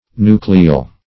Meaning of nucleal. nucleal synonyms, pronunciation, spelling and more from Free Dictionary.
Search Result for " nucleal" : The Collaborative International Dictionary of English v.0.48: Nucleal \Nu"cle*al\, Nuclear \Nu"cle*ar\, a. 1.